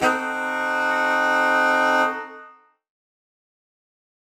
UC_HornSwellAlt_Cdim.wav